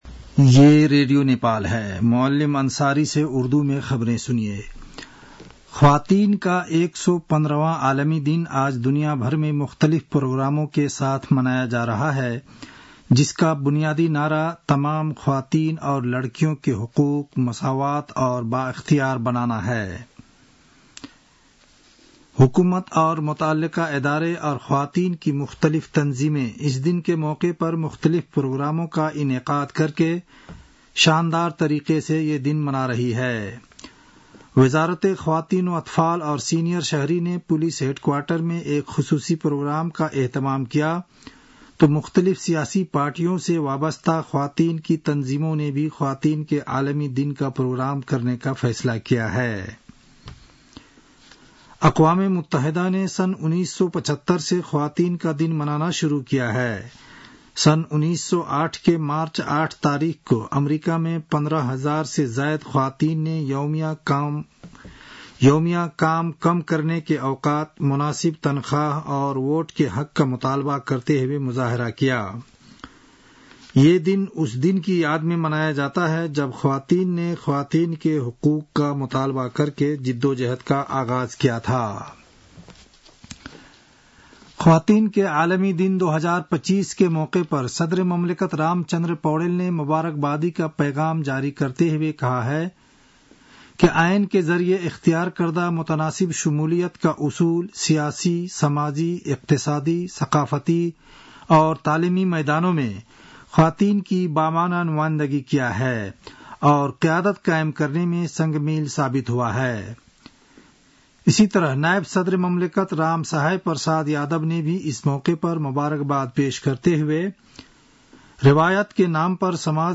उर्दु भाषामा समाचार : २५ फागुन , २०८१